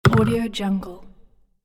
دانلود افکت صدای جابجایی ظروف پلاستیکی
صدای واقعی جابجایی ظروف پلاستیکی، برای پروژه‌های واقع‌گرایانه شما
صدای جابجایی ظروف پلاستیکی، با آن حس واقع‌گرایی و کمی آزاردهندگی، می‌تواند به پروژه‌های شما جان تازه‌ای ببخشد و مخاطب را درگیر داستان کند.
16-Bit Stereo, 44.1 kHz